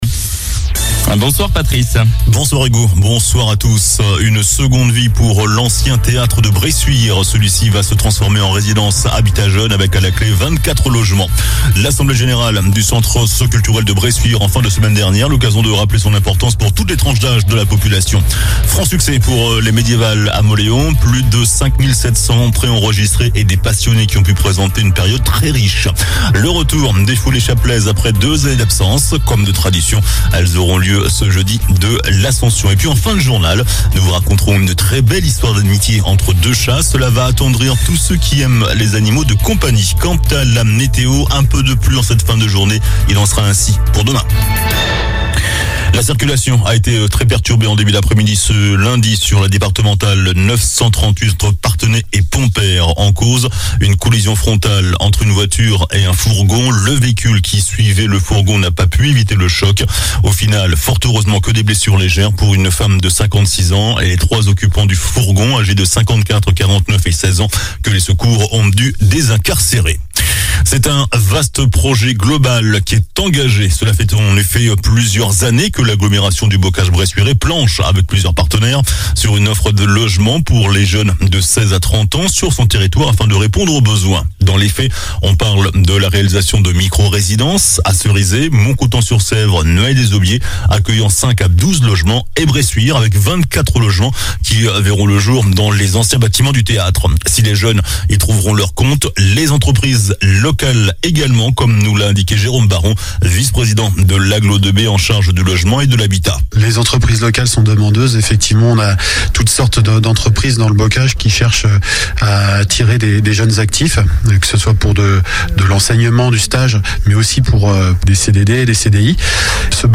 JOURNAL DU LUNDI 23 MAI ( SOIR )